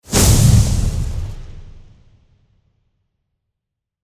FireballExplosion_2.mp3